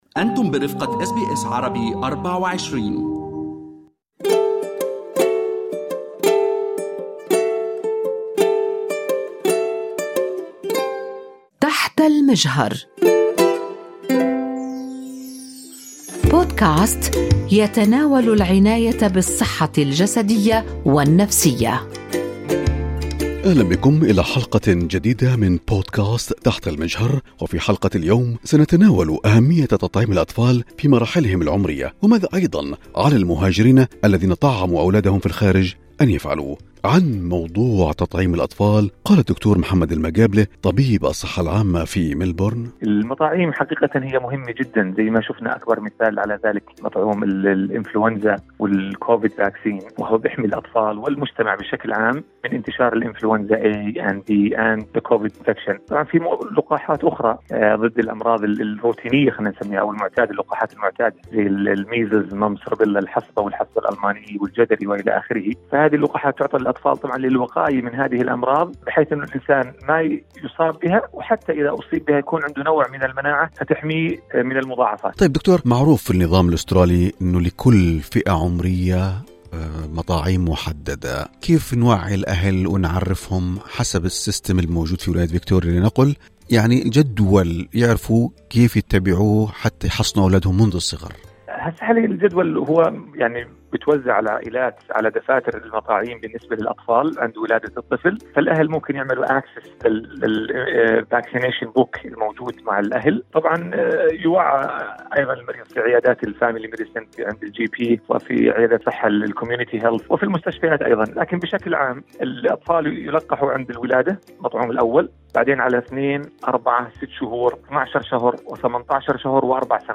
تطعيم الأطفال يحميهم من الأمراض": طبيب صحة عامة يقدم نصائح مهمة لتعزيز مناعة أبنائكم